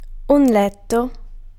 Ääntäminen
Ääntäminen France: IPA: [li] Haettu sana löytyi näillä lähdekielillä: ranska Käännös Ääninäyte Substantiivit 1. letto {m} 2. alveo {m} Muut/tuntemattomat 3. giaciglio {m} Suku: m .